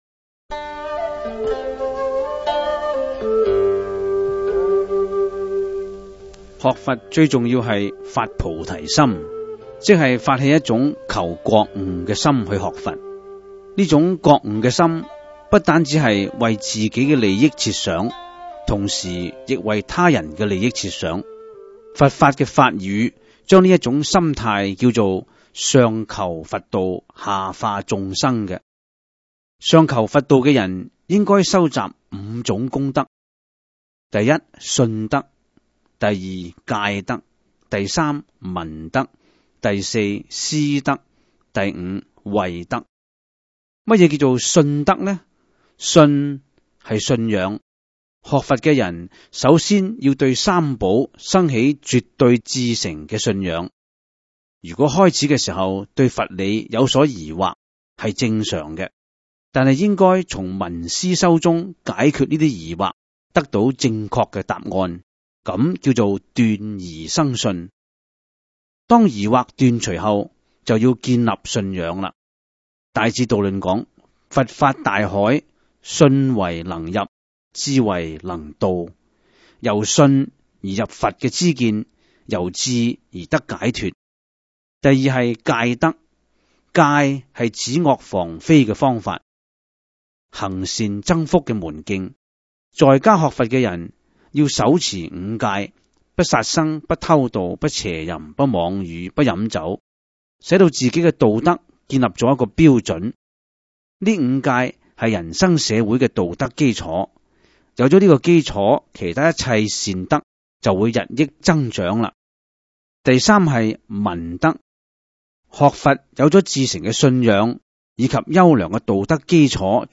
第 十 九 辑     (粤语主讲 MP3 格式)